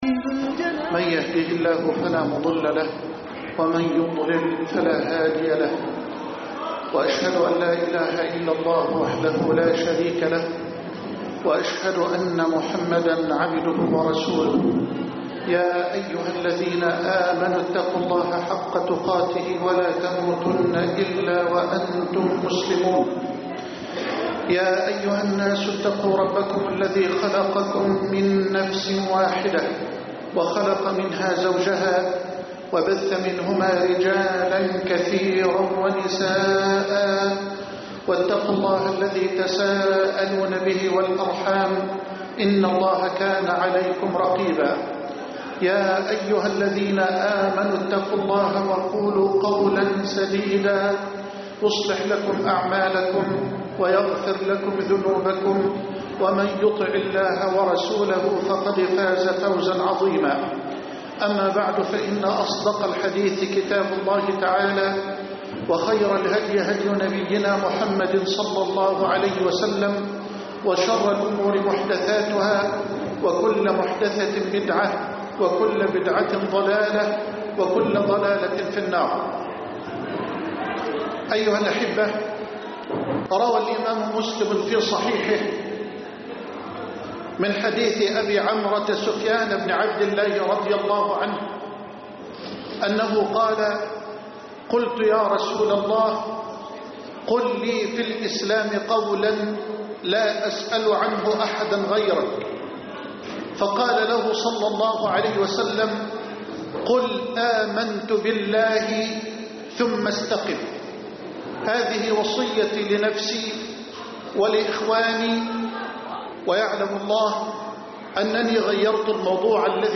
قل آمنت بالله ثم استقم (9/9/2011) خطب الجمعة - فضيلة الشيخ محمد حسان